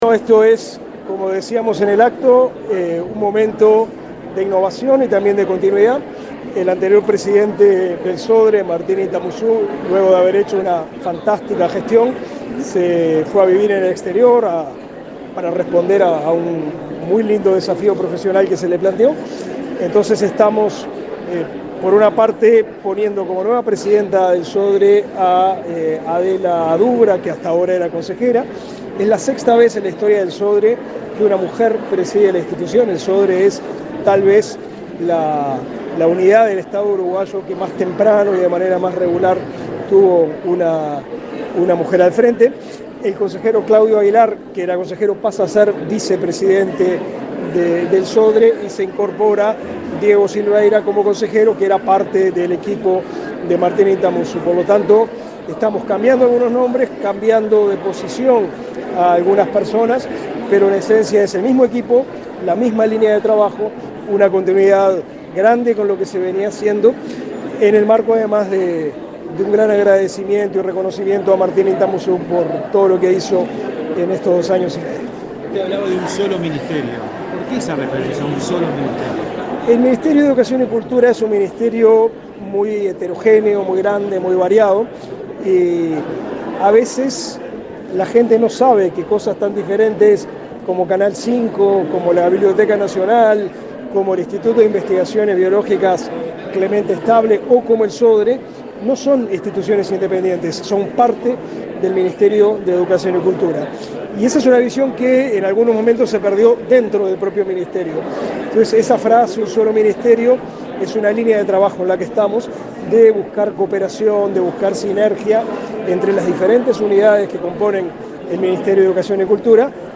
Declaraciones a la prensa del ministro de Educación y Cultura, Pablo da Silveira